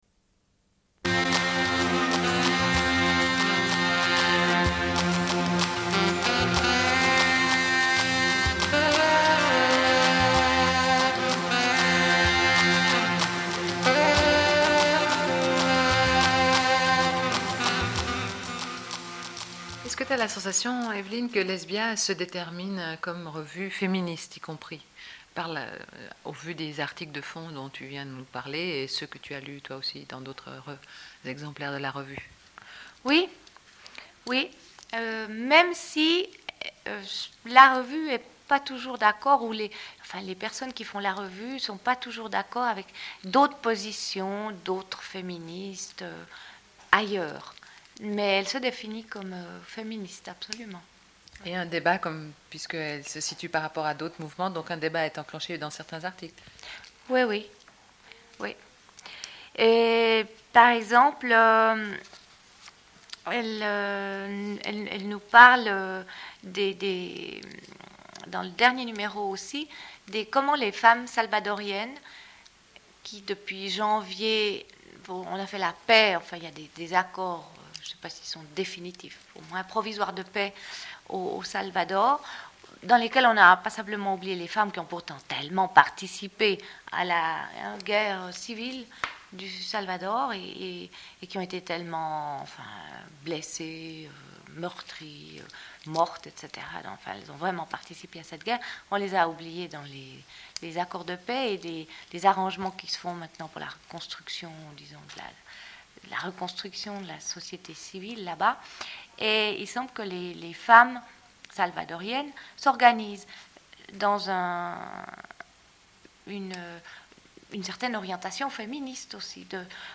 Une cassette audio, face B28:57